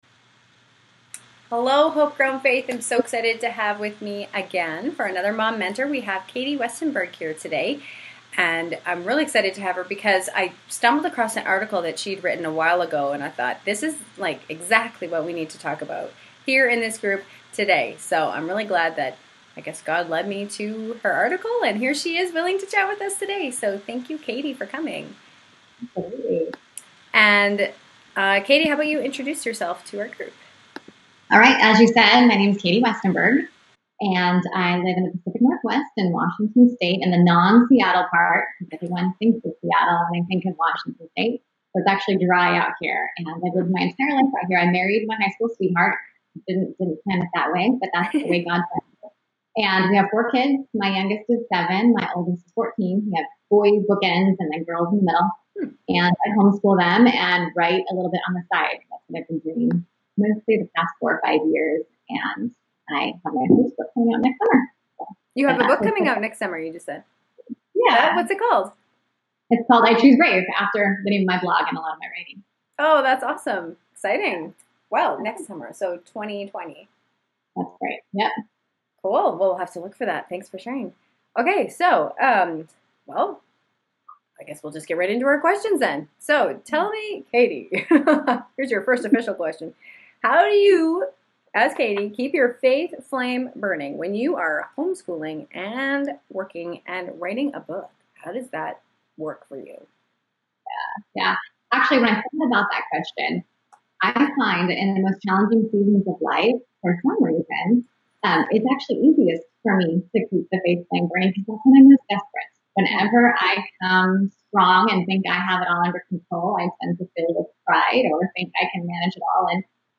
I apologize that the audio isn’t very good.